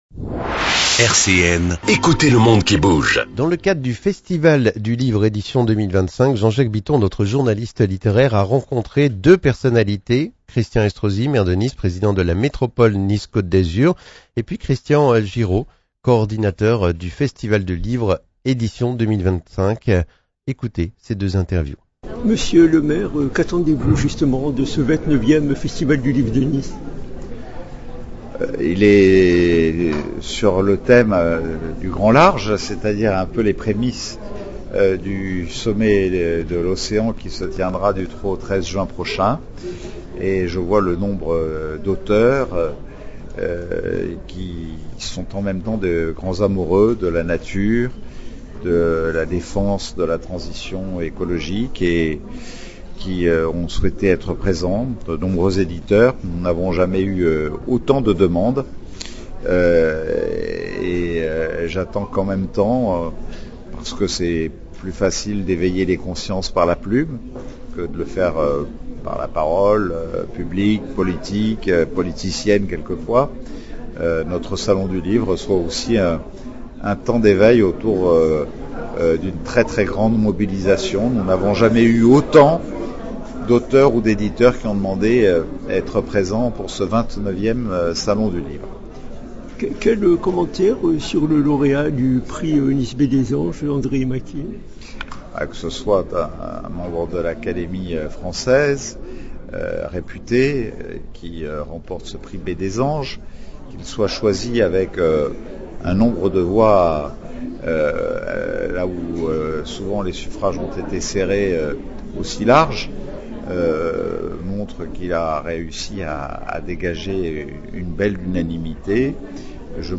Ecoutez le monde qui bouge Interviews